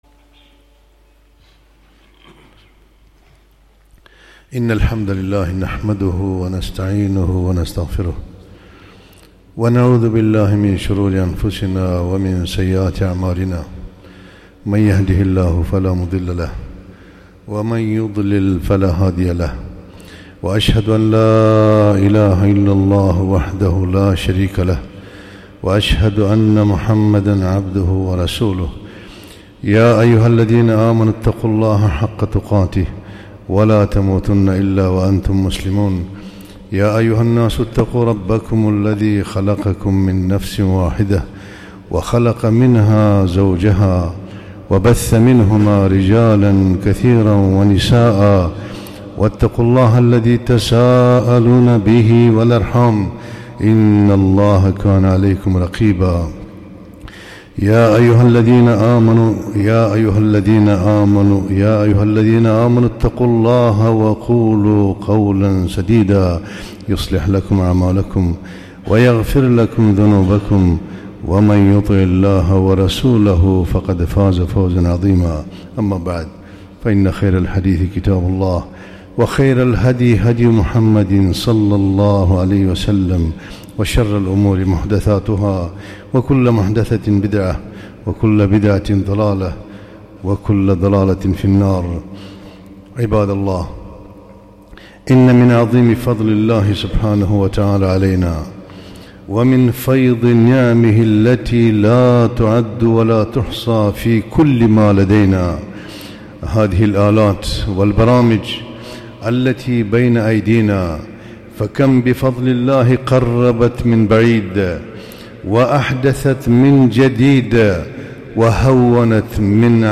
خطبة - الحذر مما في وسائل التواصل من الخطر